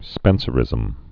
(spĕnsə-rĭzəm) also Spen·ce·ri·an·ism (spĕn-sîrē-ə-nĭzəm)